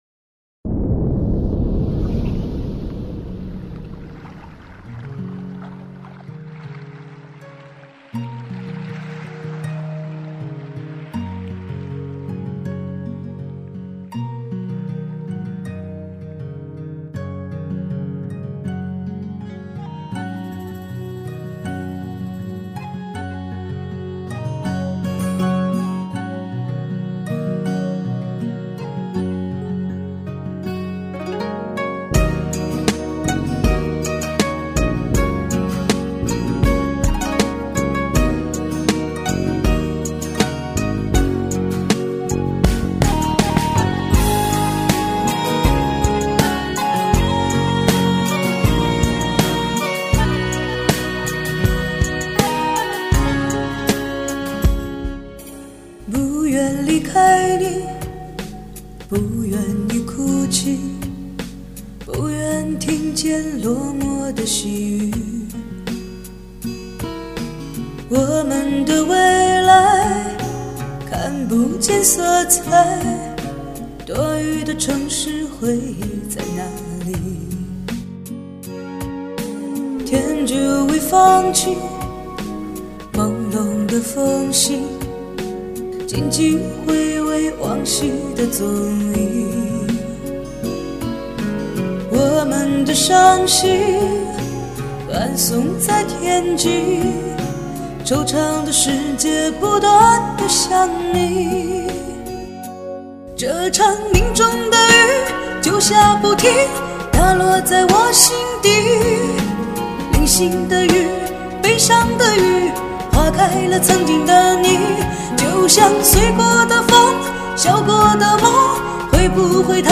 也因此而喜欢上她那种质感的声音